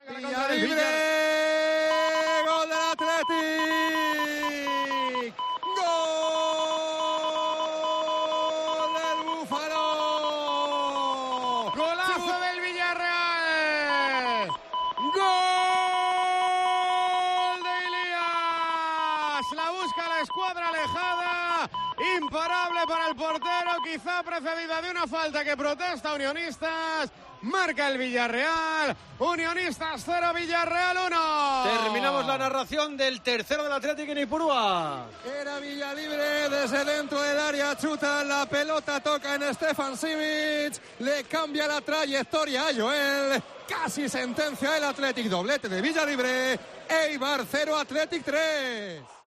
Gol de Villalibre narrado